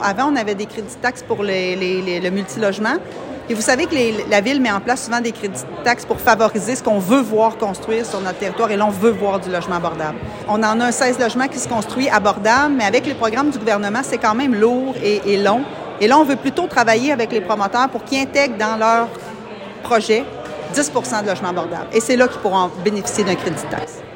Lors du Dîner de la mairesse qui se tenait à l’Hôtel Montfort jeudi, Geneviève Dubois a expliqué comment ils pourront contribuer à améliorer la situation.